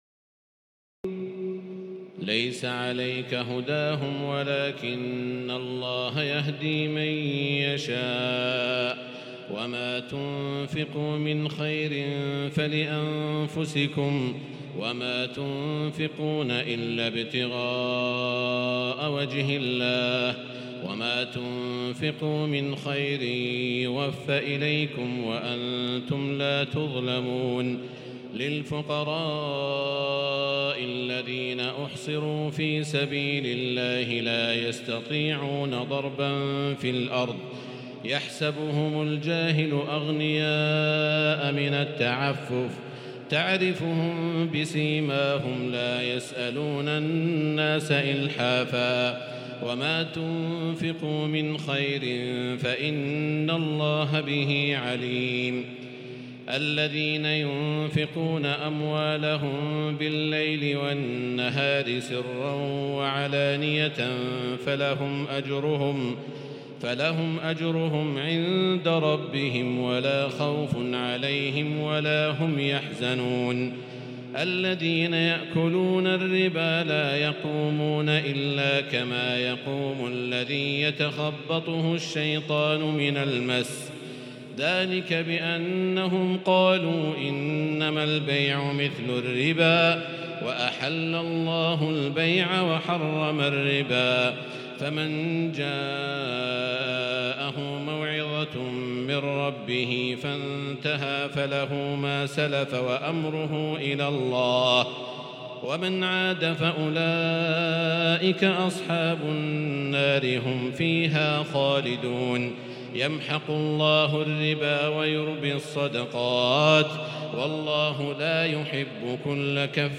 تراويح الليلة الثالثة رمضان 1438هـ من سورتي البقرة (272-286) و آل عمران (1-63) Taraweeh 3st night Ramadan 1438H from Surah Al-Baqara and Surah Aal-i-Imraan > تراويح الحرم المكي عام 1438 🕋 > التراويح - تلاوات الحرمين